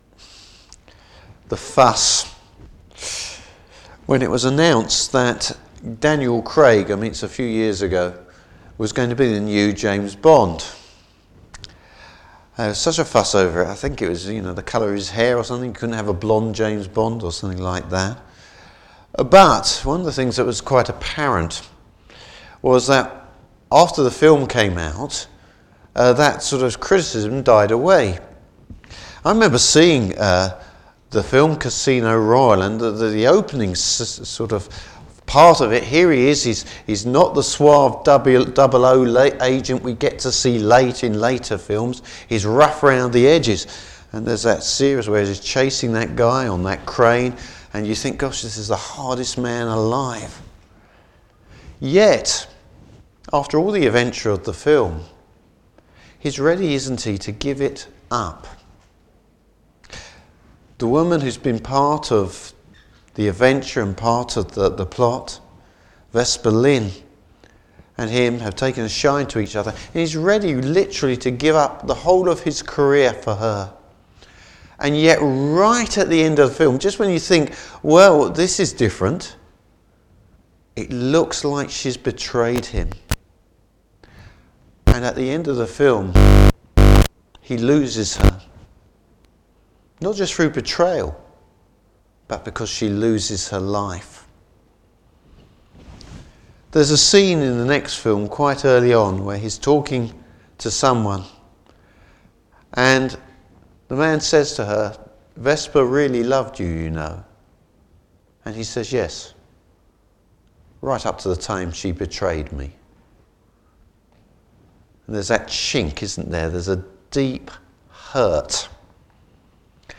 Service Type: Morning Service Bible Text: Psalm 55.